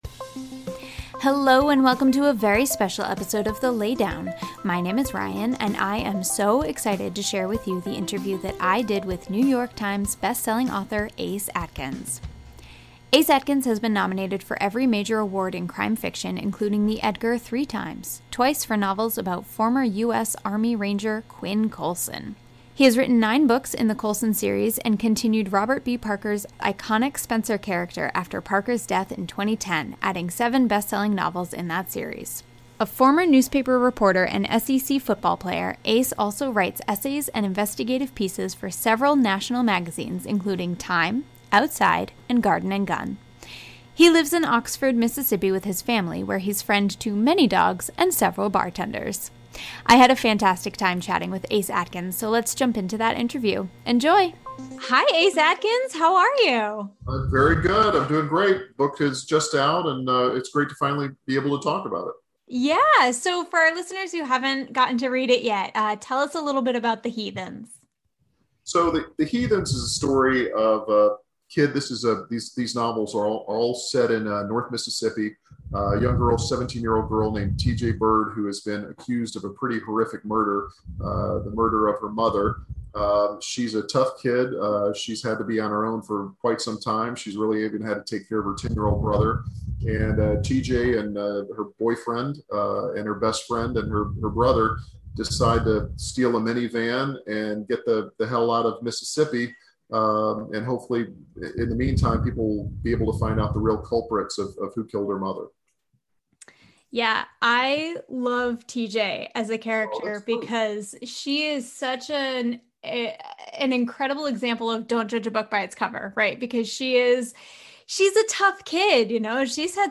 Ace Atkins Interview!